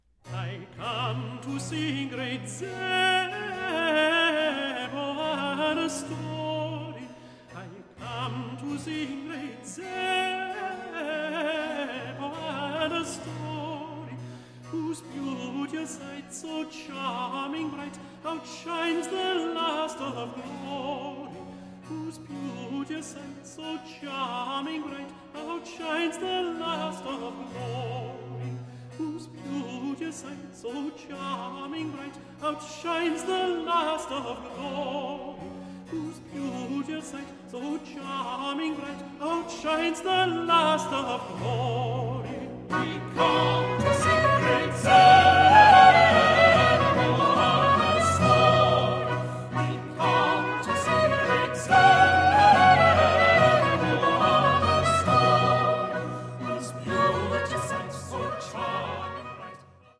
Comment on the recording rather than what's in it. "Beautifully recorded."